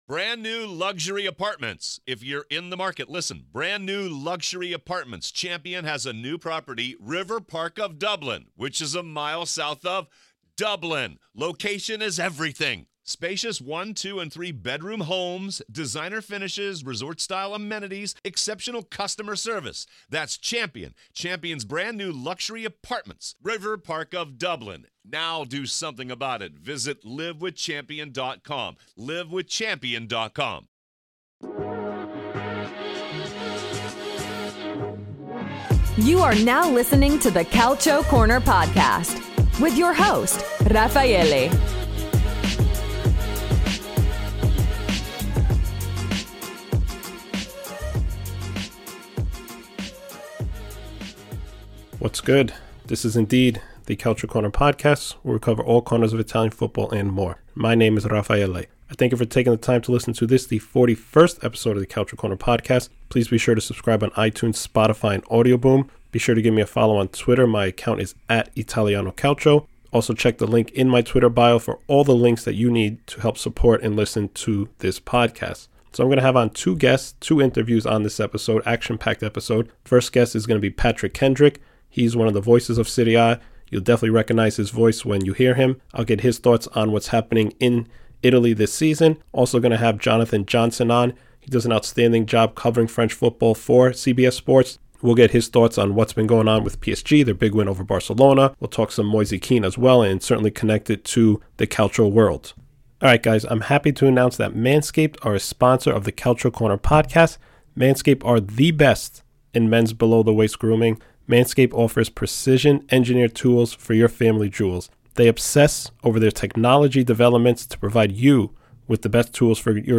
Episode 41 - Juventus Lose in UCL + Double Interview